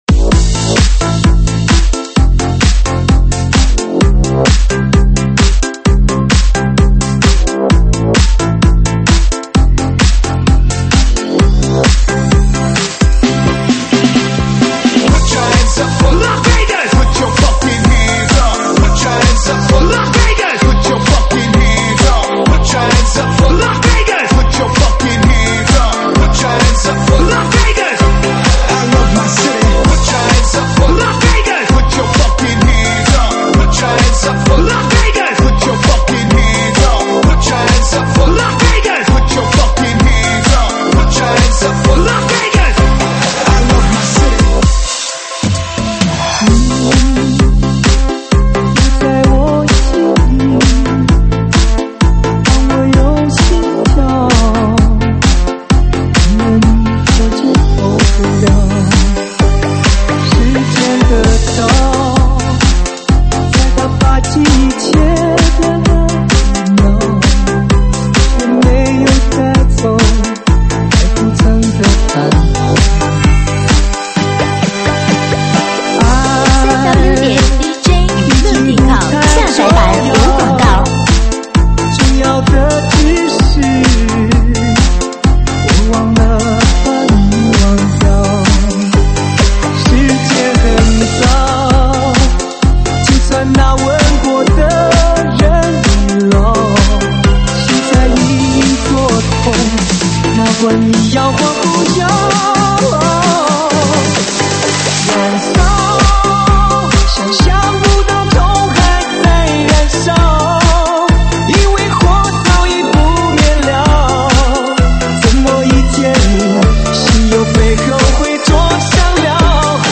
现场串烧
舞曲类别：现场串烧